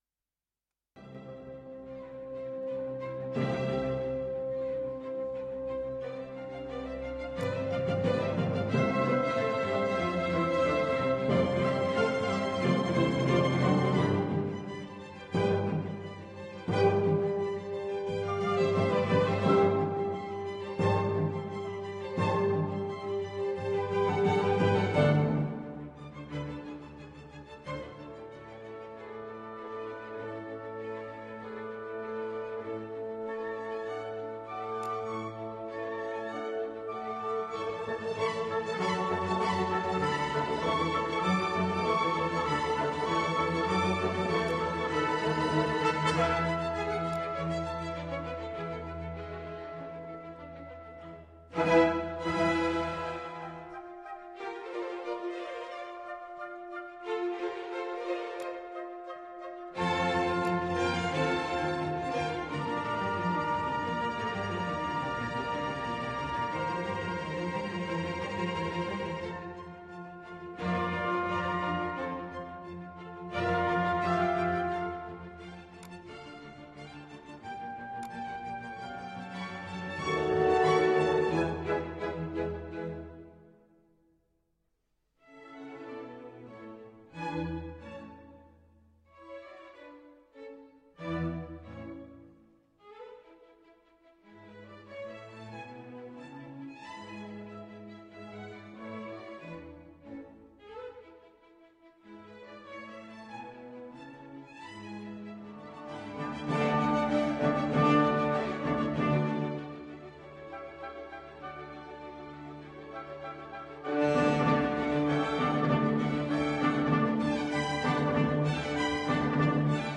Sinfonia funebre, Sinfonia in tre movimenti